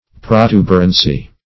Search Result for " protuberancy" : The Collaborative International Dictionary of English v.0.48: Protuberancy \Pro*tu"ber*an*cy\, n. The quality or state of being protuberant; protuberance; prominence.
protuberancy.mp3